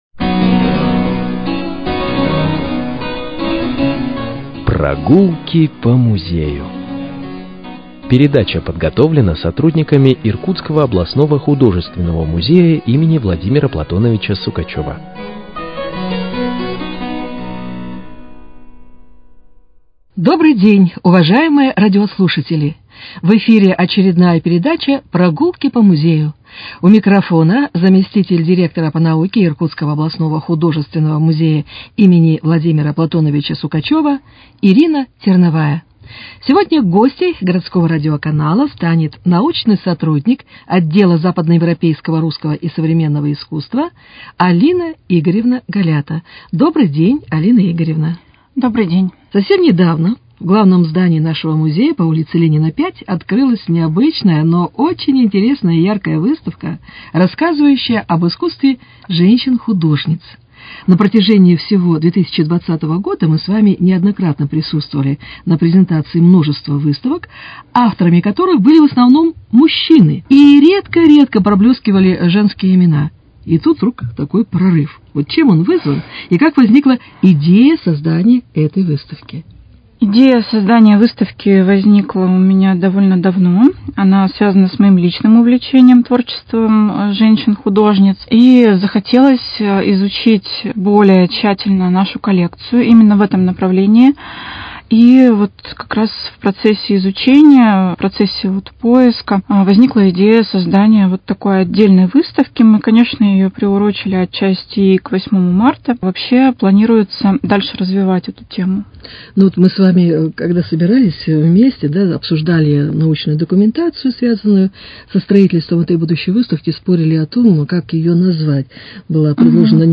Передача из авторского цикла